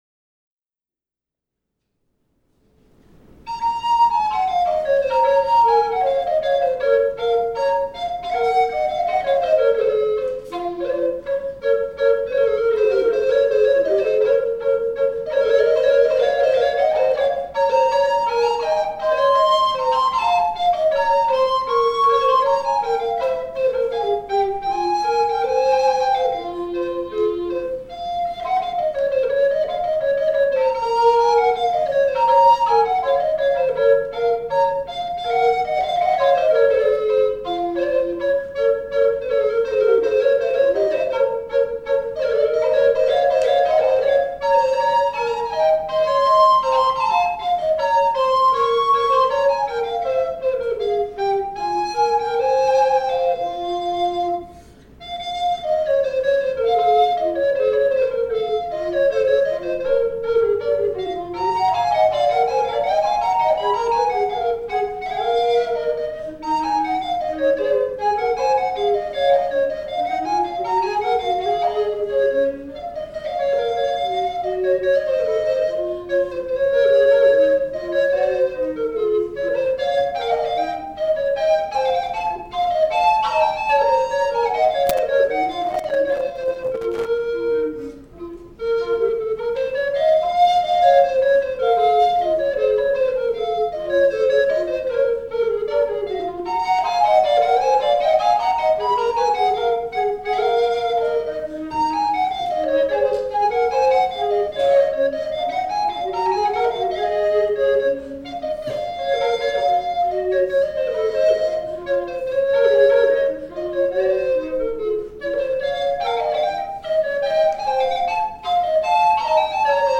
過去の演奏のご紹介
Recorder